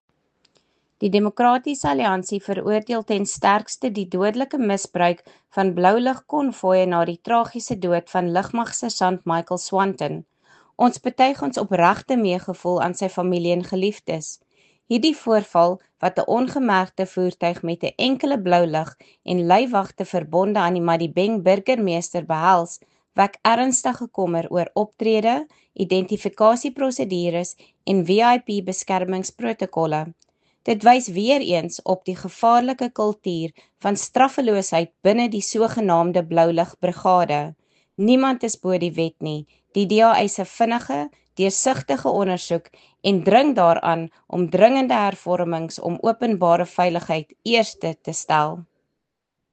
Afrikaans soundbite by Lisa Schickerling MP